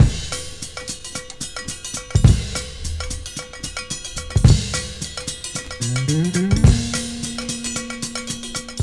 • 109 Bpm Drum Groove A# Key.wav
Free breakbeat - kick tuned to the A# note. Loudest frequency: 1948Hz
109-bpm-drum-groove-a-sharp-key-TBQ.wav